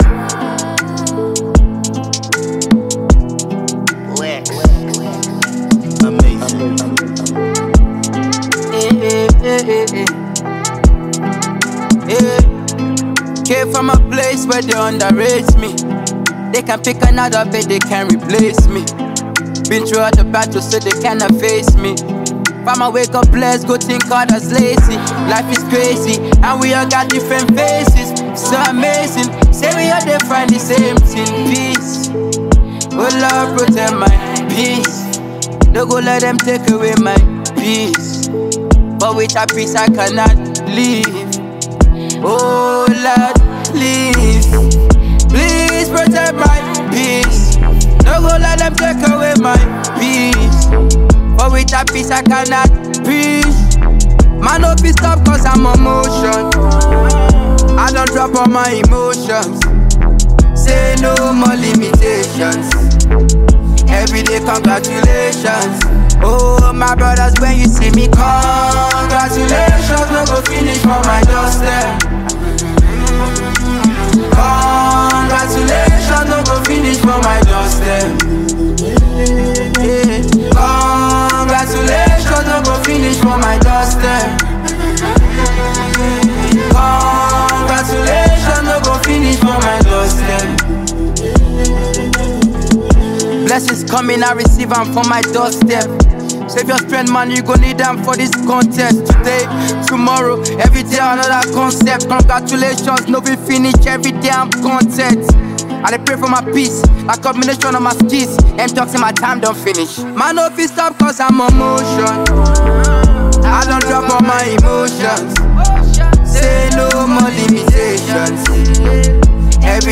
Talented Nigerian rapper